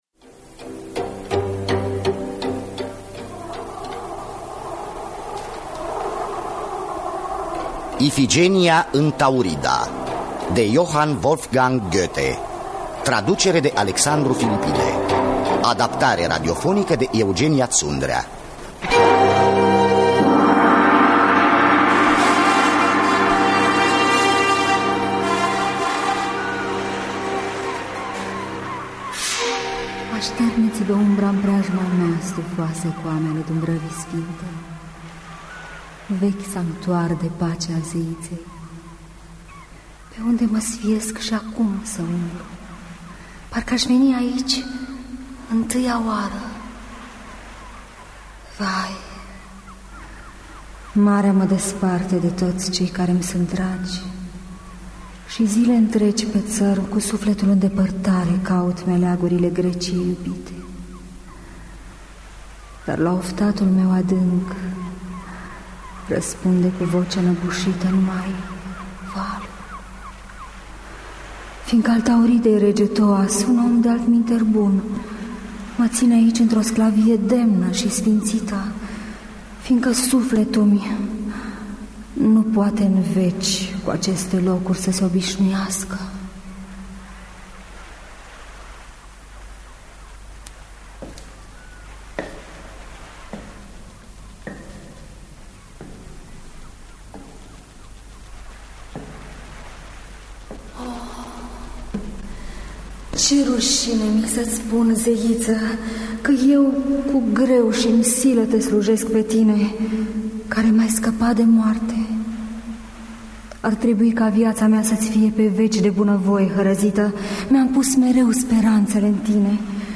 Adaptare radiofonică